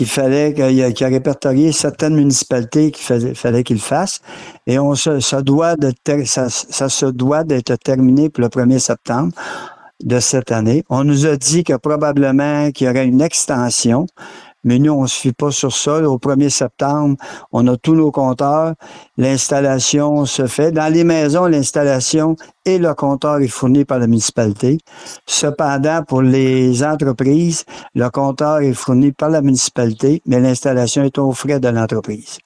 Le maire, Raymond Noël, a expliqué que c’est le gouvernement qui a demandé à prendre ces données et que d’autres villes et municipalités ont également eu cette demande.
M. Noël a confirmé que les citoyens n’auront pas à débourser pour l’installation de ces compteurs.